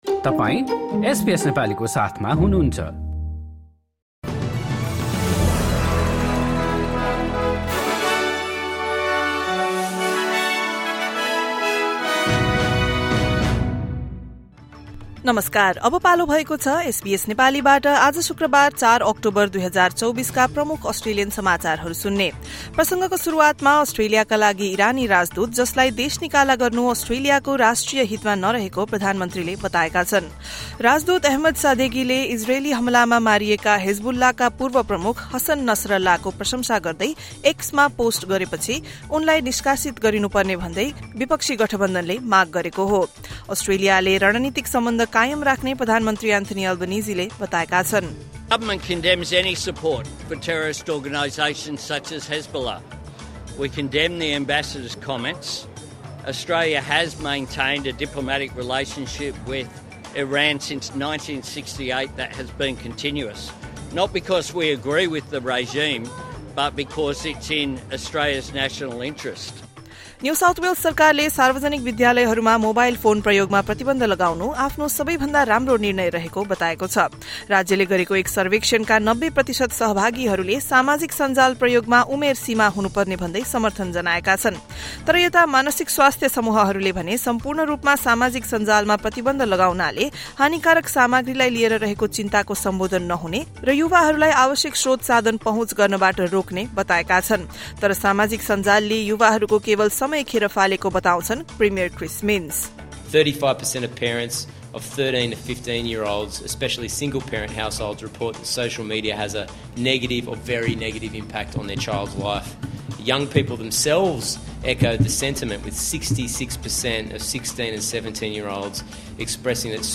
SBS Nepali Australian News Headlines: Friday, 4 October 2024